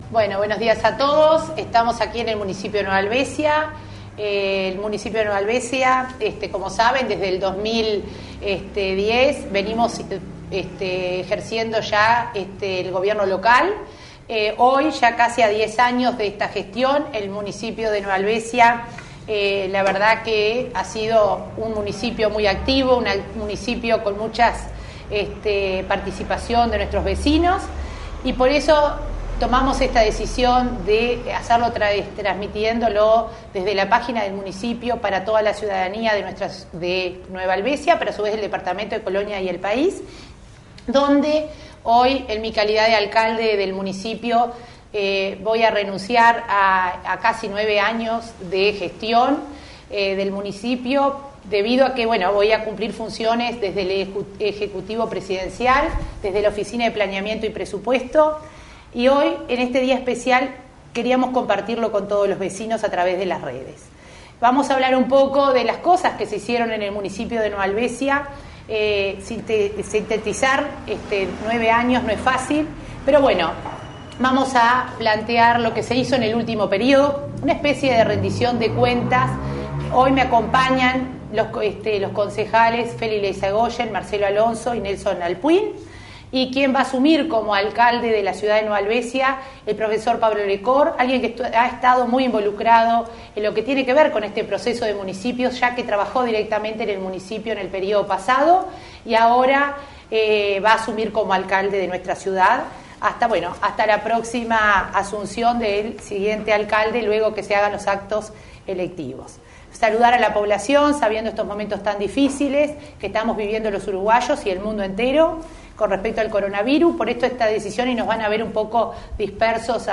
Sin público debido a la situación sanitaria actual, se hizo el traspaso, donde De Lima hizo un repaso de su gestión.